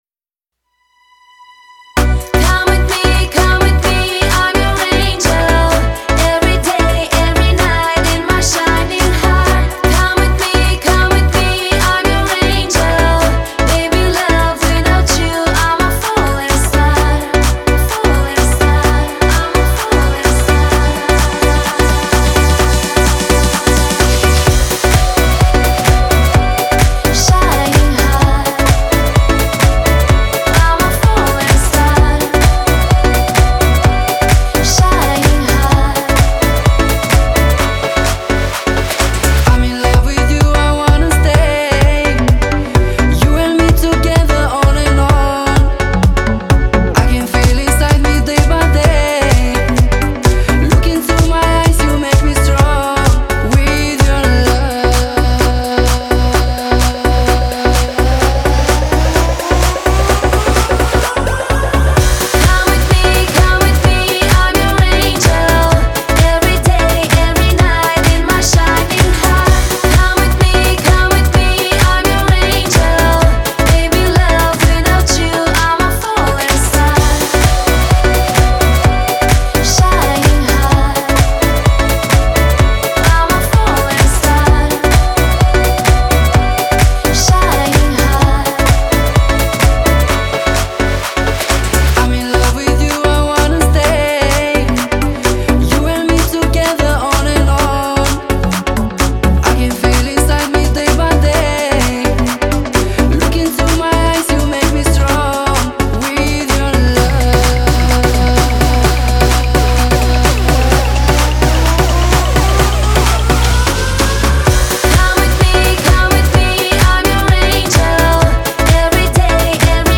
Стиль: Dance